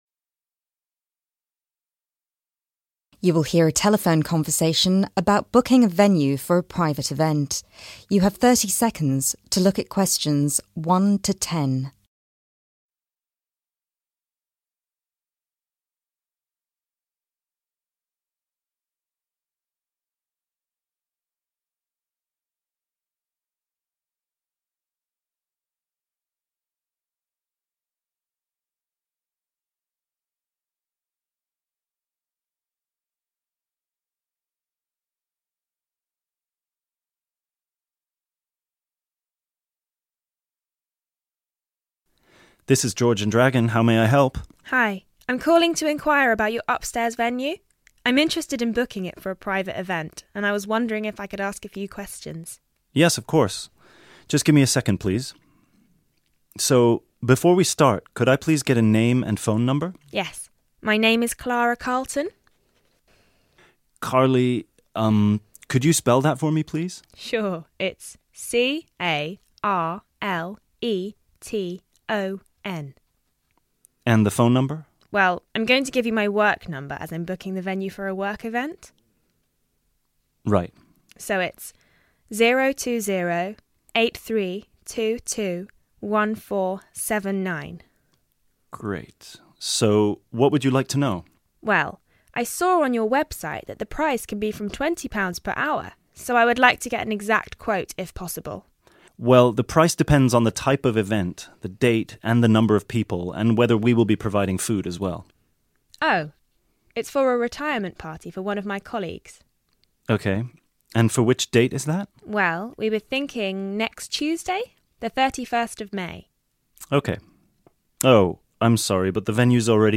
IELTS Listening Section 1
IELTS Listening Section 2 Questions 11-15 You are going to hear a speech given at a poetry award ceremony.